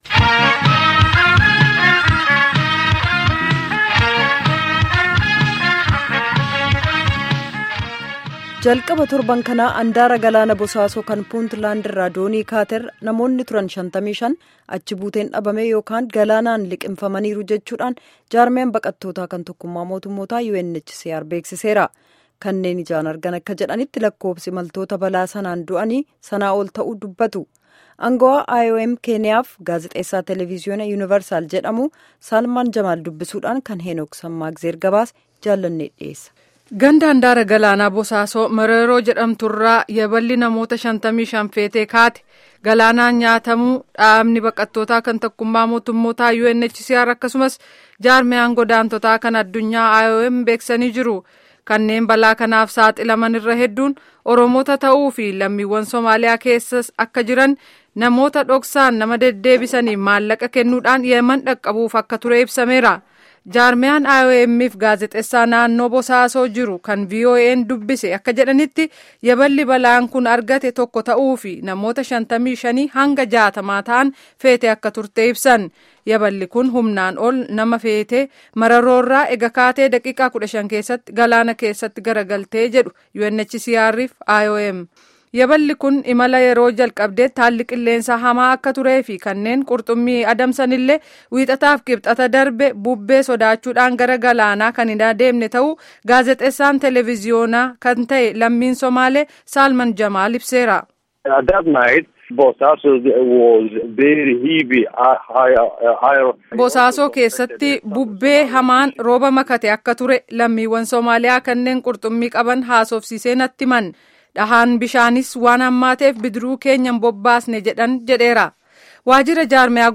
Gaaffii fi deebii kutaa 3ffaa armaan gaditti caqasa